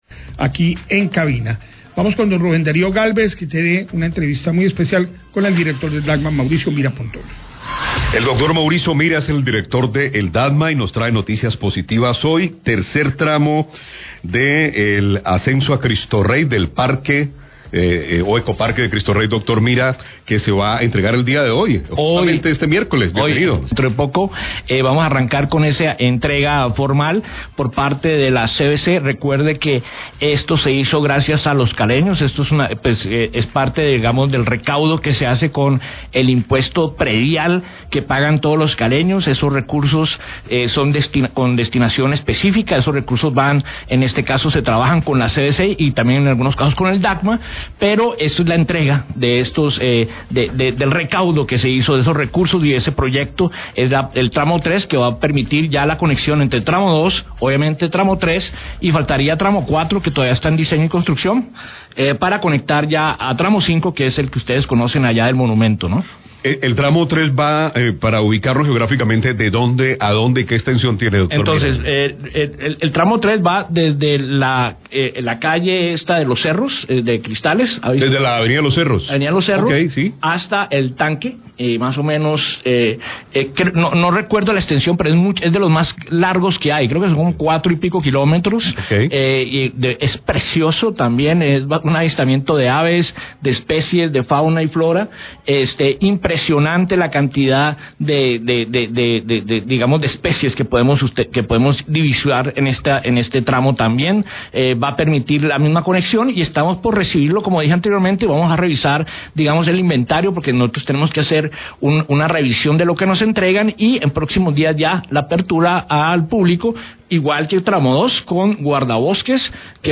Radio
El director del DAGMA, Mauricio Mira, y el secretario de Gobierno, Diego Hau, hablaron acerca de la entrega del tramo 3 del corredor ambiental Cristo Rey.